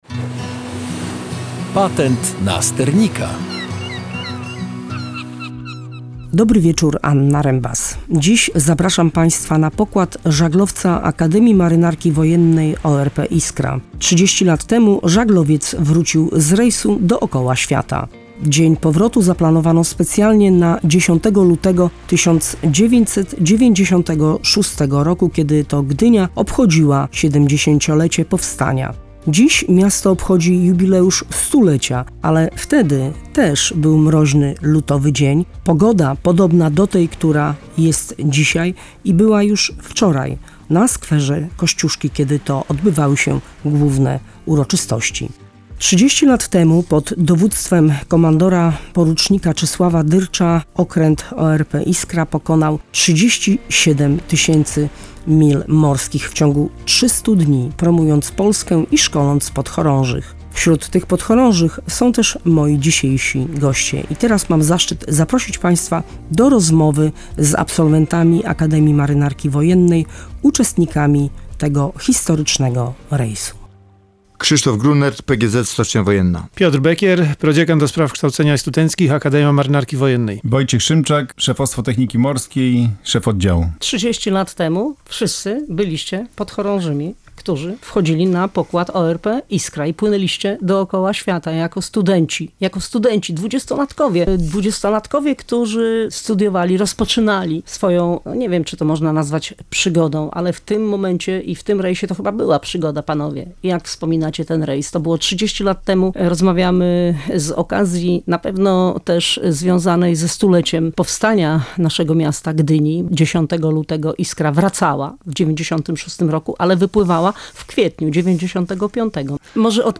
ówcześni podchorążowie z pierwszego roku Wydziału Nawigacji